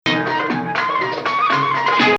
Rast 4